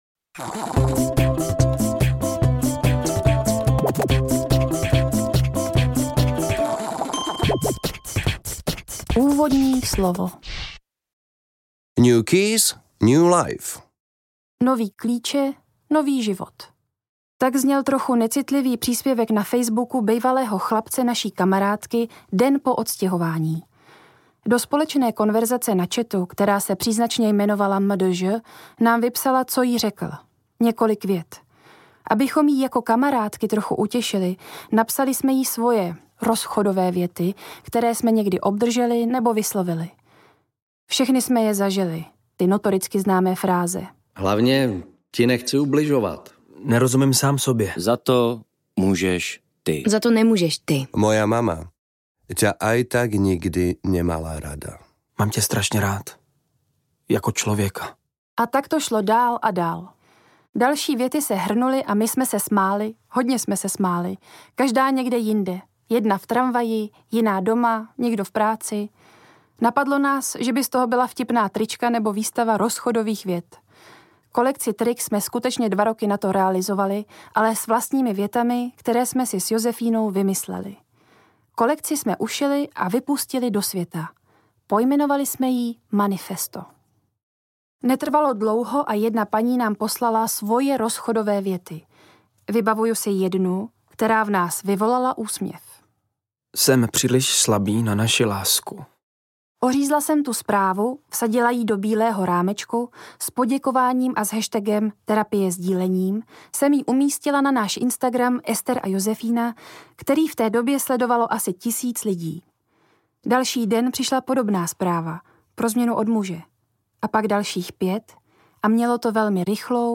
Terapie sdílením audiokniha
Ukázka z knihy